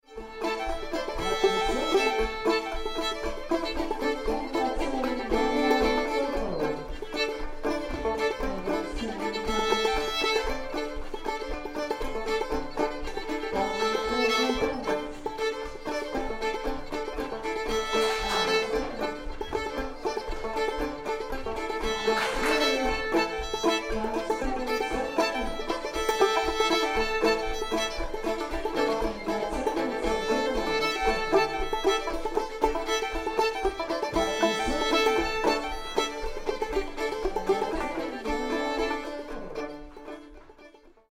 Bed of Coals reel
live performance audio of Bed of Coals, at a contra dance
banjo
fiddle
One chord per measure